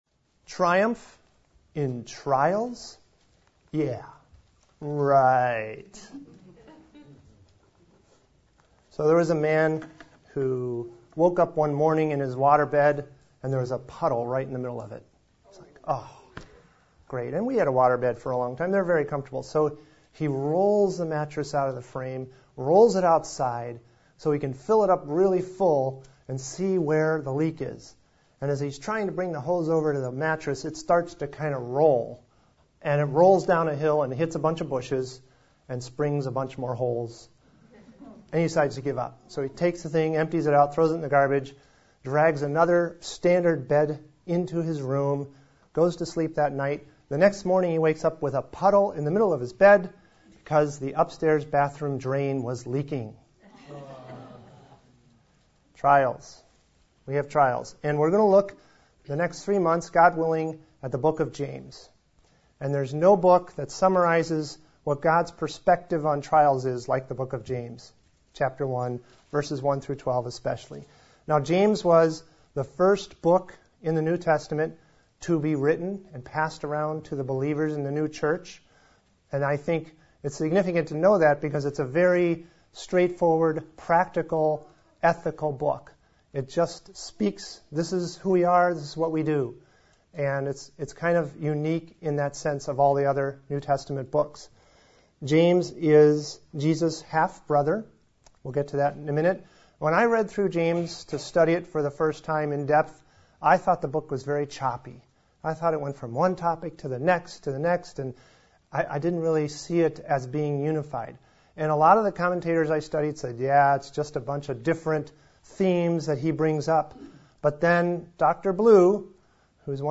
Sermons | Hartford Bible Church